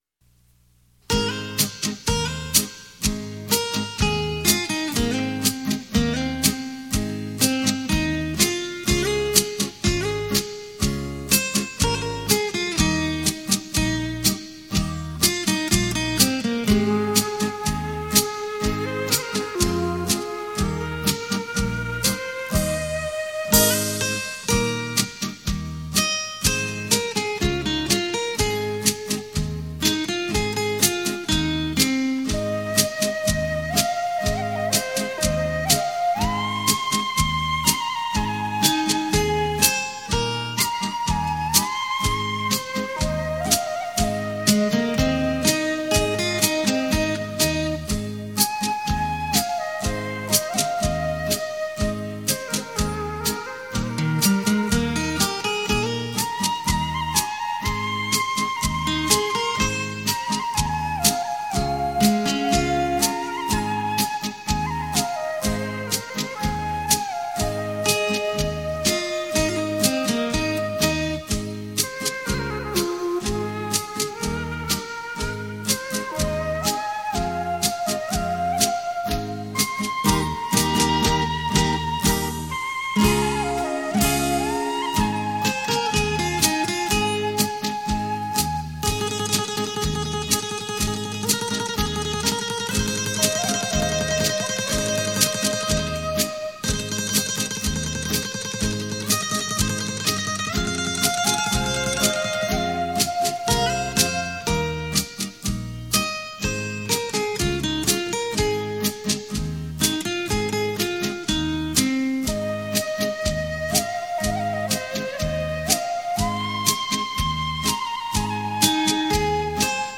音乐类型: 民乐
立體效果 環繞身歷聲 超魅力出擊 全新風格精心製作